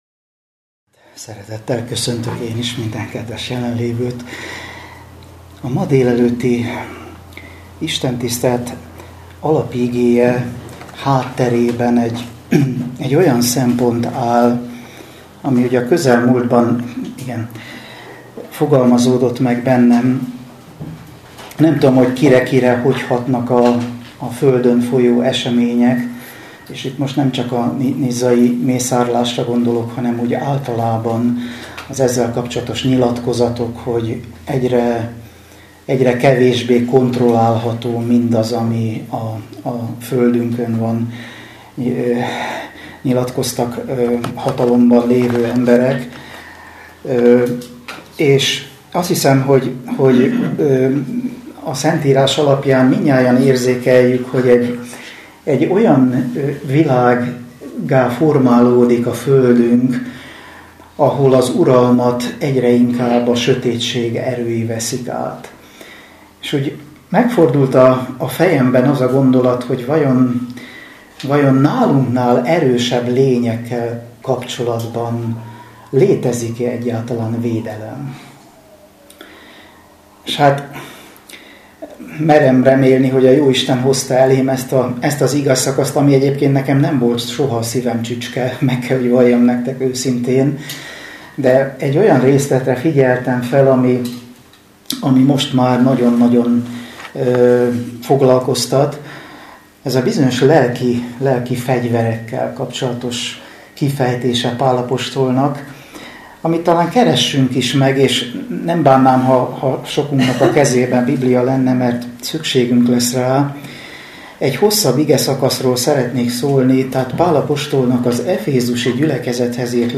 Biatorbágy
Szombati igehirdetés 2016